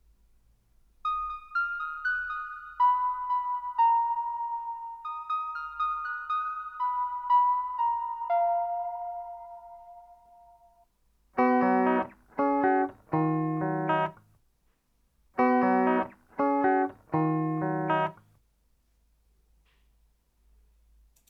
При записи фонит аудиокарта и микрофое
Использую для записи звуковую карту taskam us-122mk2 (по USB) и ноутбук заряженный отключенный от зарядки, микрофон Audio-Technica AT2020 (XLR), на всех записях присутствует ощутимый фон, который сильно раздражает.
Пример записи с полезным сигналом прикладываю (без обработок, сырая запись), фон довольно сильно слышно в наушниках.
Вложения Сырая запись без обработок.wav Сырая запись без обработок.wav 2,9 MB · Просмотры: 211